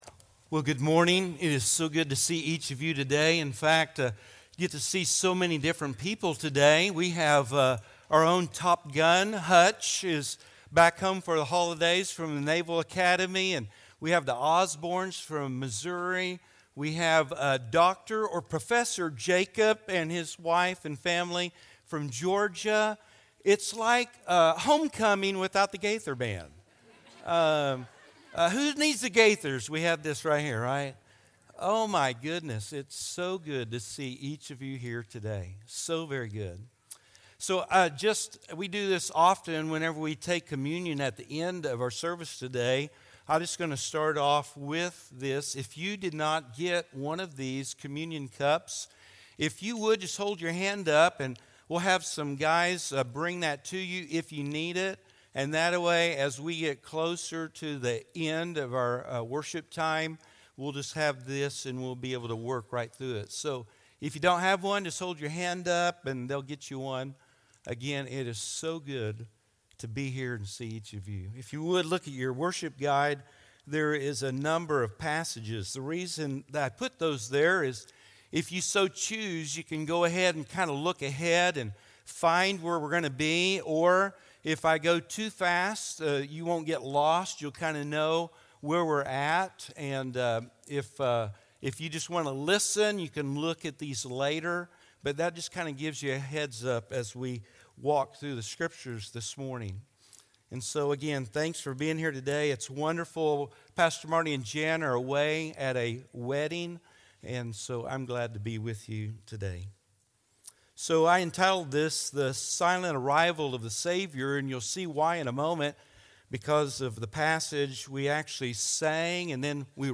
Sermons – HERITAGE Baptist Church